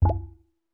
DrumQuietBamboo Pop Notification.wav